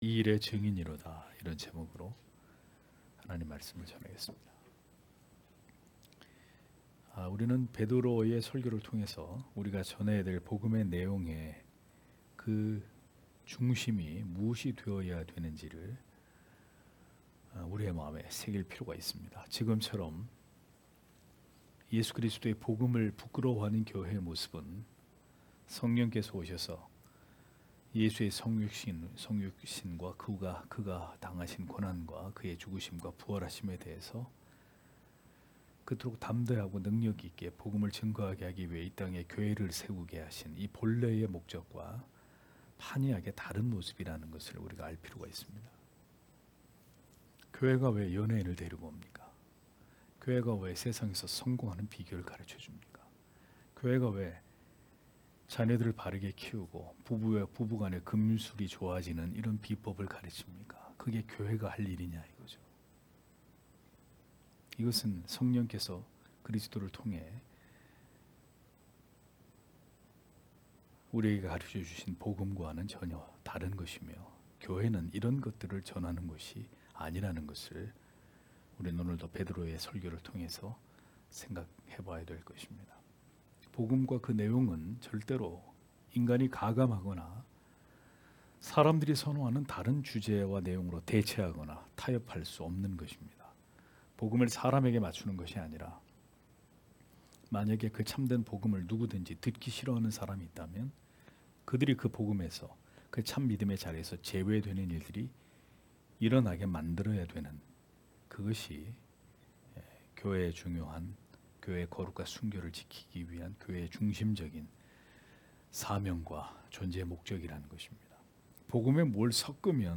금요기도회 - [사도행전 강해 15] 이 일에 증인이로다 (행 2장 29- 32절